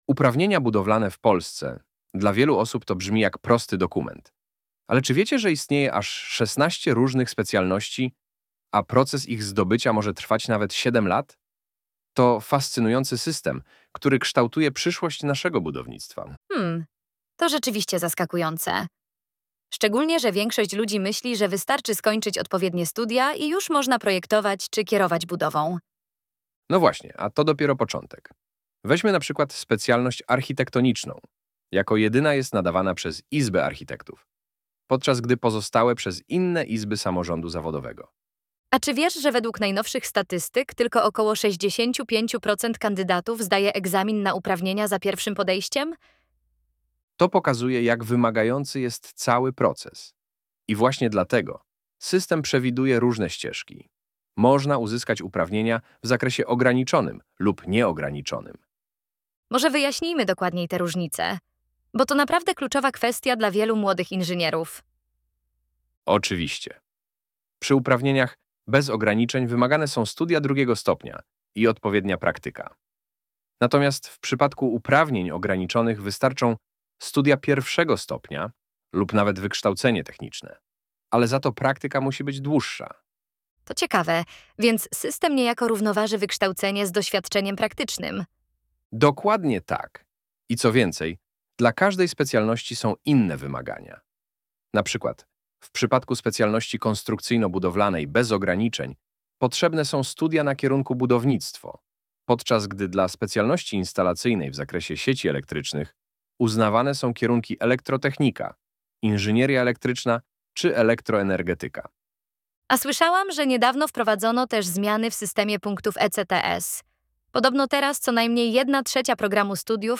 Poznajcie naszych wirtualnych inżynierów Chrisa i Jessicę , którzy opowiedzą wam wiele ciekawych historii w poniższych podcastach 😃